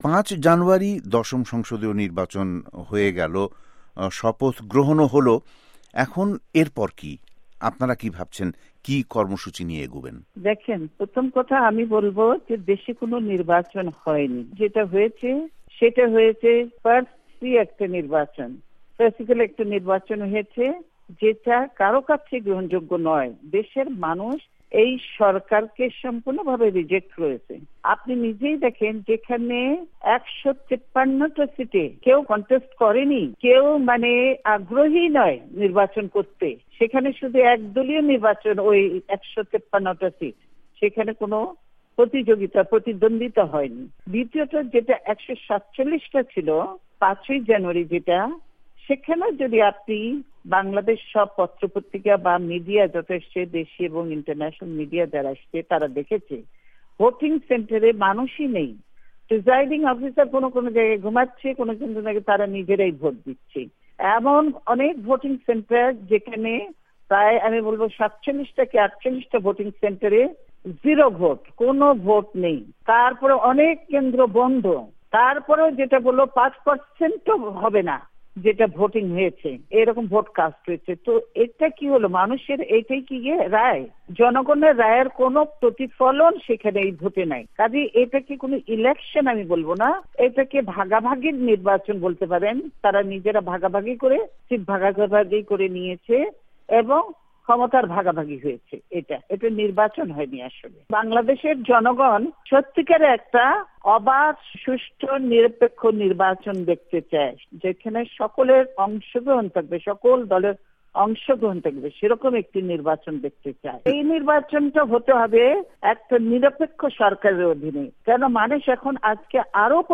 BNP-র চেয়ার পার্সন বেগম খালেদা জিয়ার সঙ্গে একান্ত সাক্ষাৎকার
বেগম জিয়ার সাক্ষাৎকার